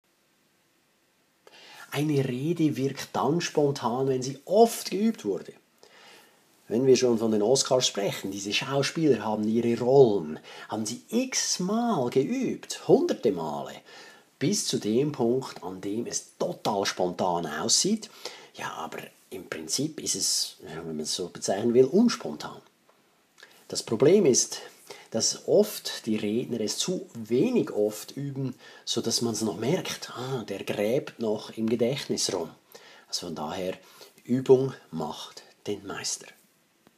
Dankesrede 6